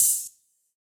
Index of /musicradar/ultimate-hihat-samples/Hits/ElectroHat D
UHH_ElectroHatD_Hit-27.wav